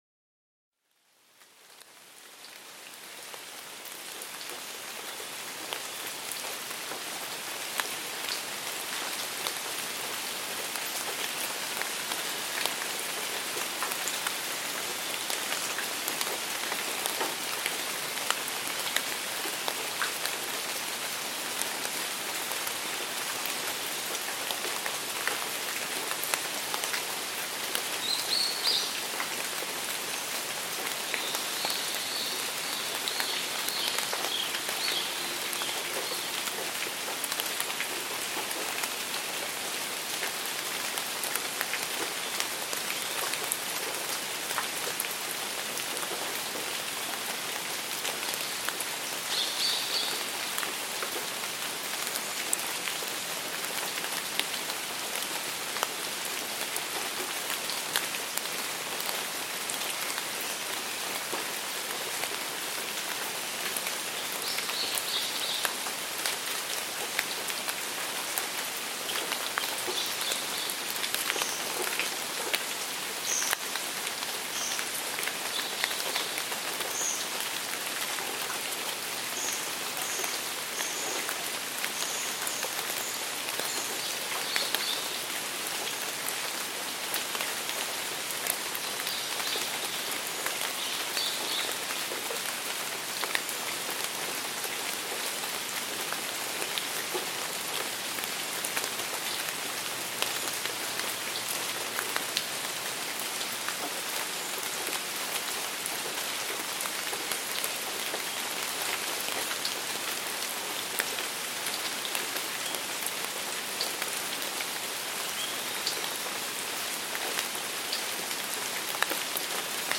Natural rain sounds for deep concentration
rainvoice.CcWrIgmn.mp3